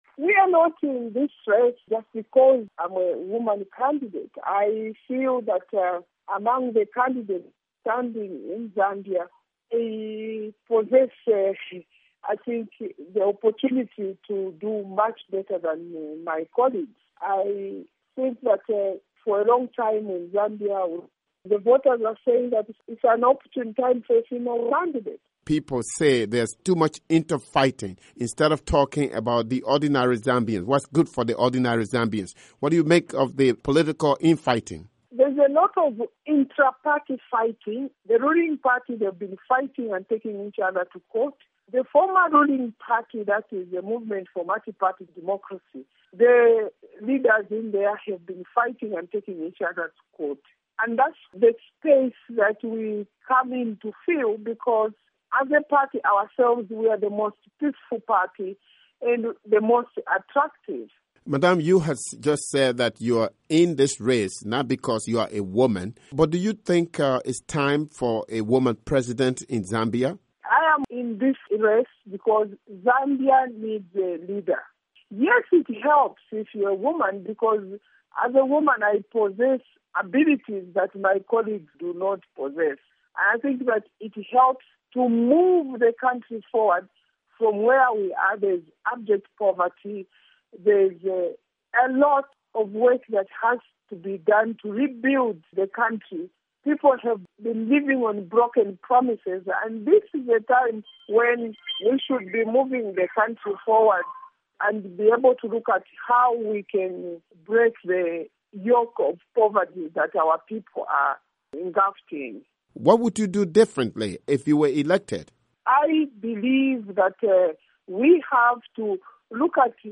interview with Edith Nawakwi of Zambia